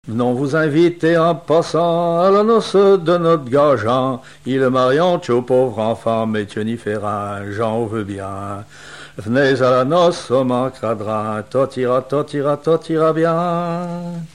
Chapelle-Achard (La)
Genre laisse
Pièce musicale inédite